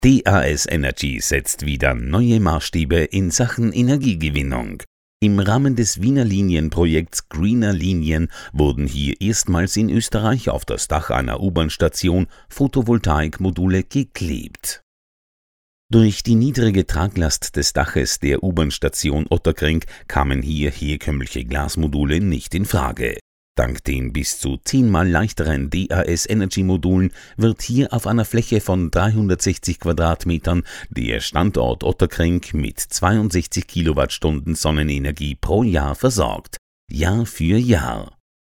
Freundliche, tiefe Männerstimme in den besten Jahren, schnell und flexibel - vom Telefonspot bis zur Stationvoice.
Sprechprobe: Industrie (Muttersprache):
Friendly deep male Voice, since 1997 on air, well known in Austria and Germany from Shopping TV Media Shop and many other productions.